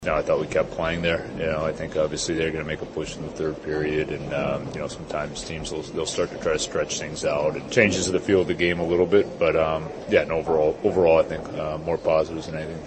Pens Coach Dan Muse says he’s happy with how his team played after taking a big lead early.